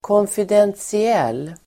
Ladda ner uttalet
konfidentiell adjektiv, confidential Uttal: [kånfidentsi'el:] Böjningar: konfidentiellt, konfidentiella Definition: inte avsedd för allmänheten, hemlig Exempel: konfidentiella uppgifter (confidential information)